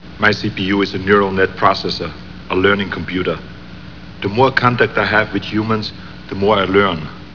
COMPUTER VOICE FILES